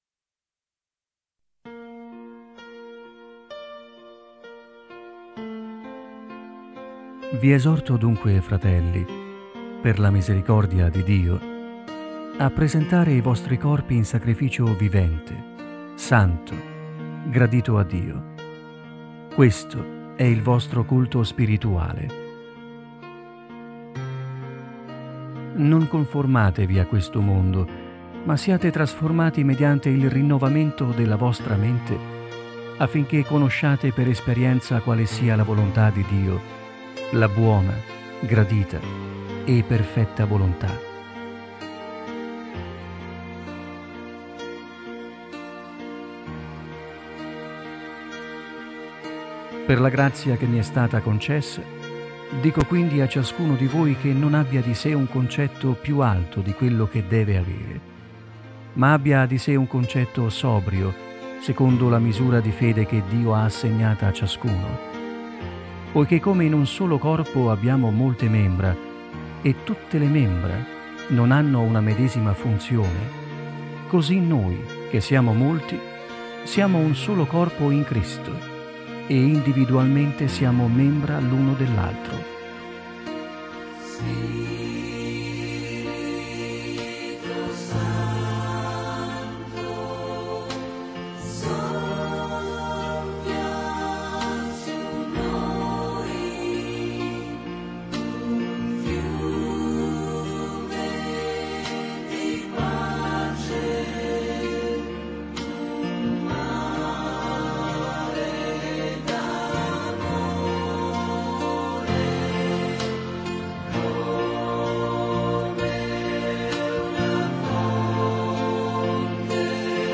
Letture della Parola di Dio ai culti della domenica